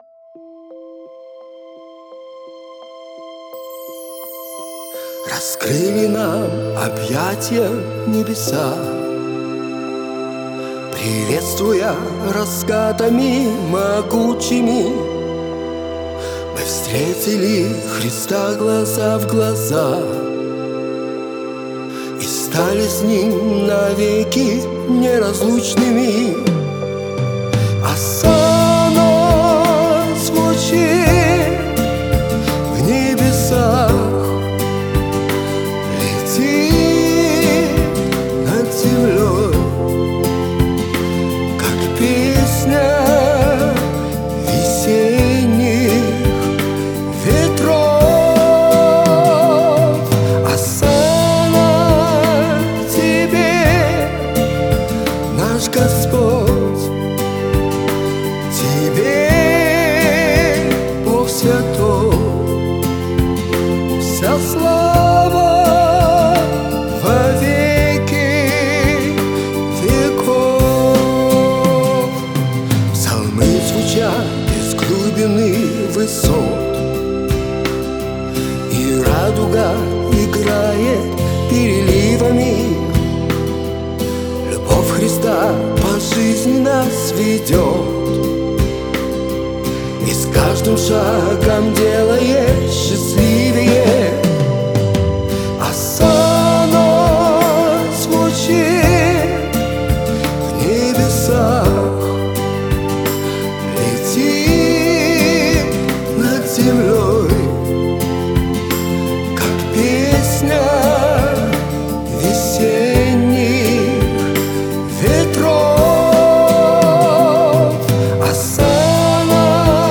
136 просмотров 222 прослушивания 14 скачиваний BPM: 85